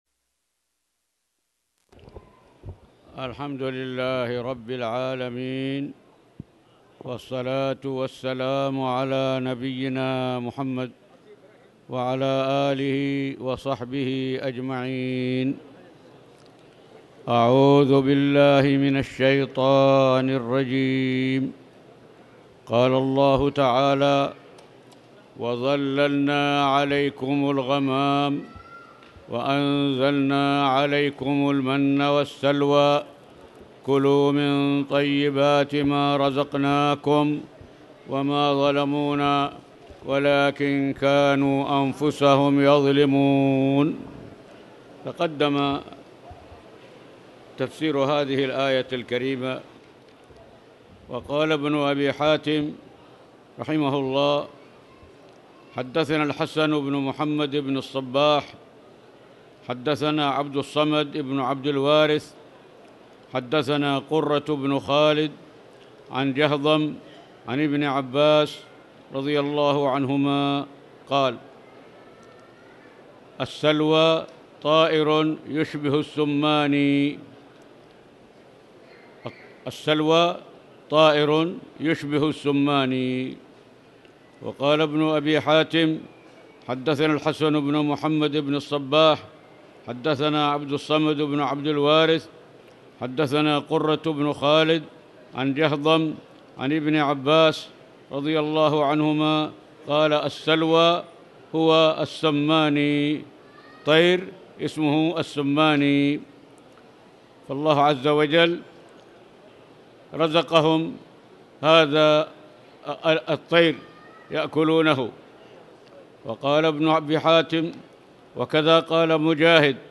تاريخ النشر ١٣ محرم ١٤٣٨ هـ المكان: المسجد الحرام الشيخ